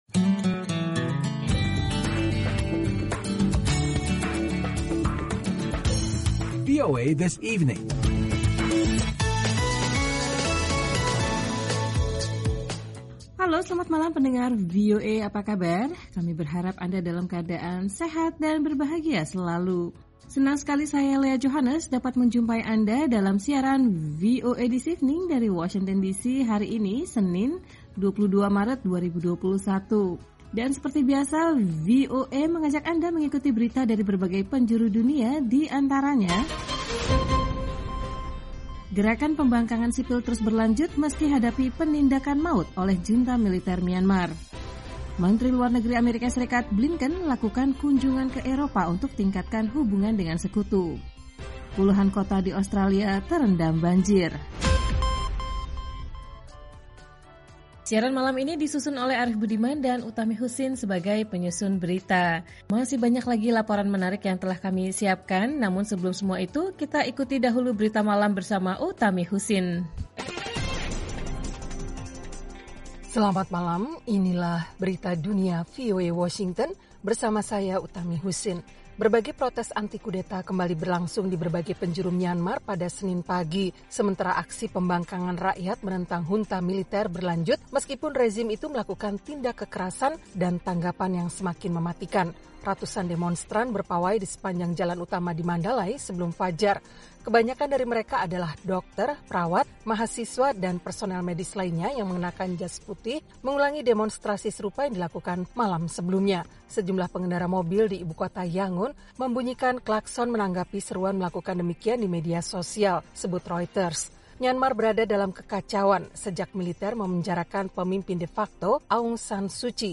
Siaran VOA This Evening 22 Maret 2021